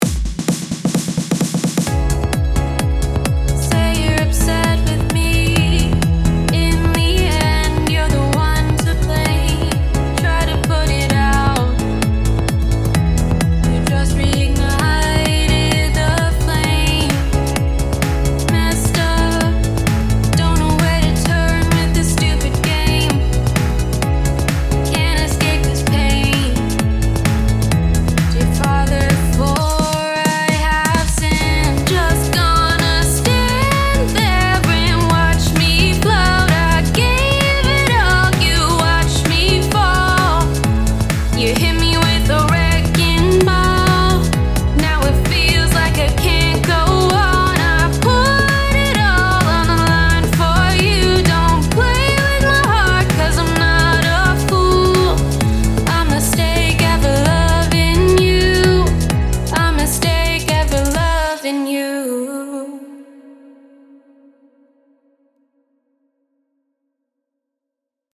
使ったボーカルのせいで EDM というよりはバラードっぽくなりましたが、なかなかいい感じです。